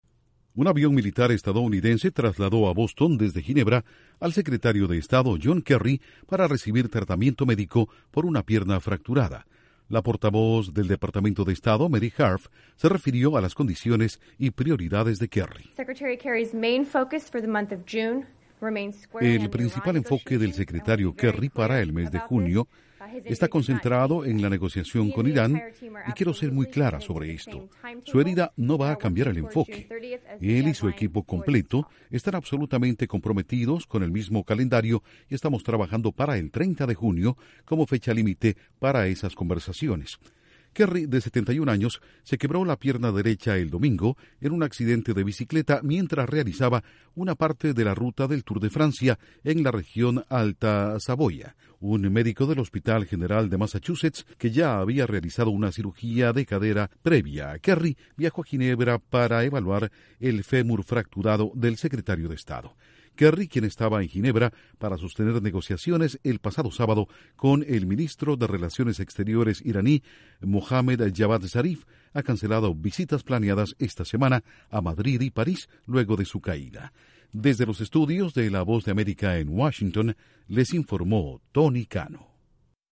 Regresa a Estados Unidos el Secretario de Estado, John Kerry, luego de un accidente en bicicleta en Ginebra. Informa desde los estudios de la Voz de América en Washington